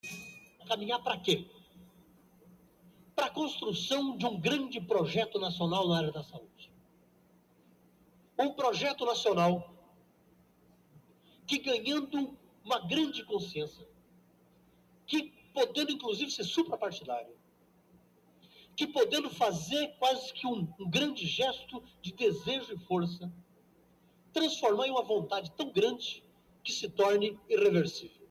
Discurso de Sergio Arouca na 8ª Conferência Nacional de Saúde, em 1986.
discurso-sergio-arouca-8-conferencia-nacional-saude.mp3